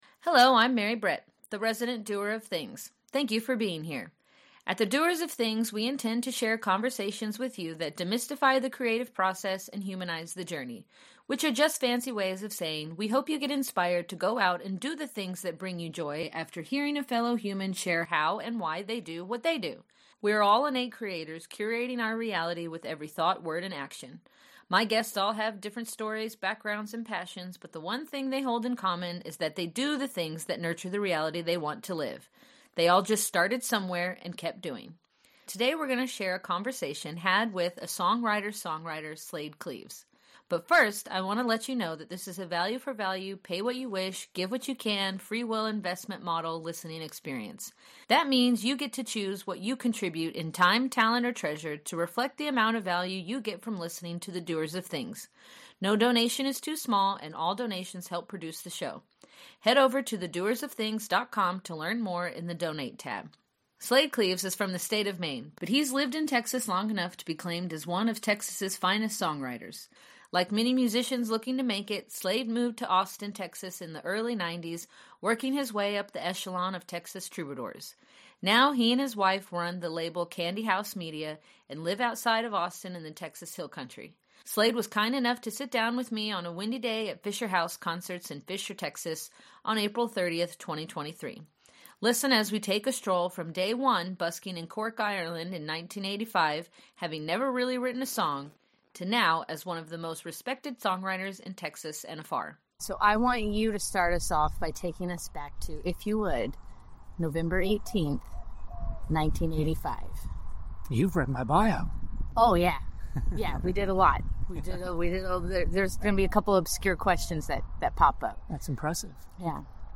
I got to sit down with Slaid Cleaves on a windy day at Fischer Haus Concerts in Fischer, Texas, on April 30, 2023. Listen as we take a stroll from day one busking in Cork, Ireland, in 1985, having never really written a song, to now as one of the most respected songwriters in Texas.